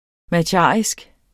Udtale [ maˈdjɑˀisg ]